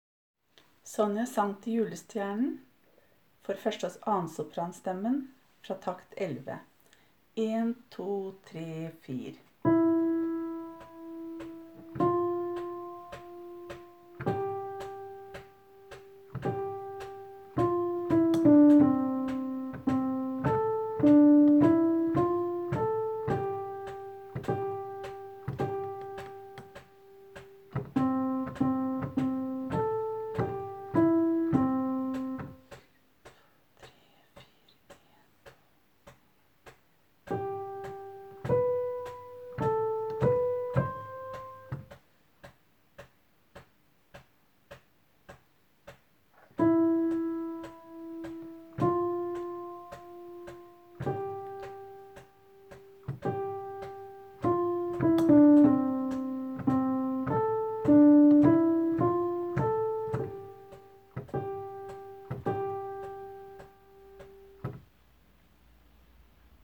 Jul 2017 Sopraner (begge konserter)
Sonjas-sang-til-julestjernen-1-og-2-Sopran.m4a